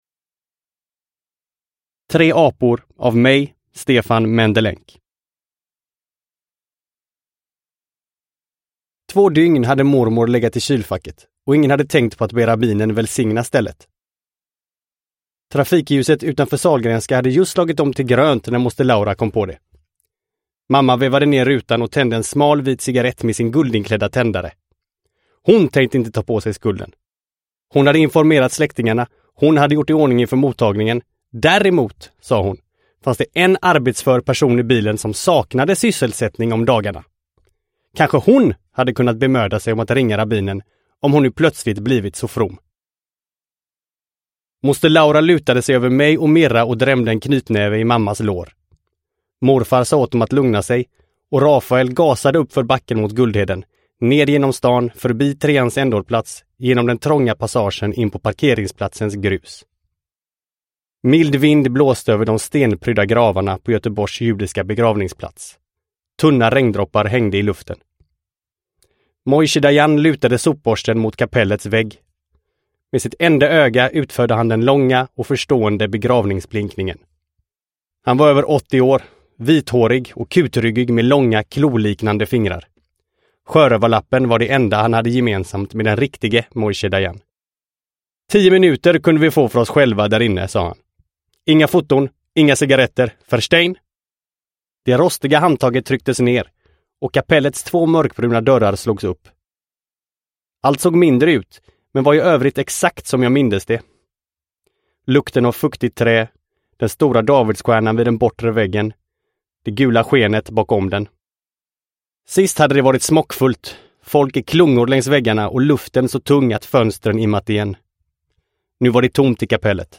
Tre apor – Ljudbok – Laddas ner